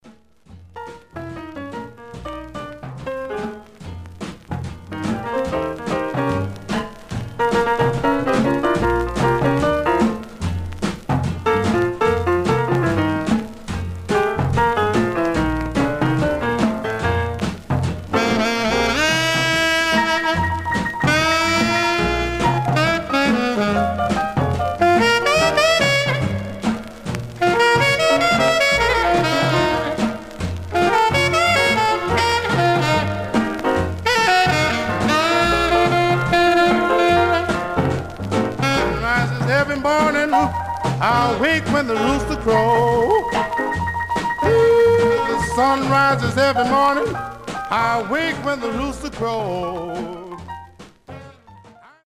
Condition Surface noise/wear Stereo/mono Mono
Rythm and Blues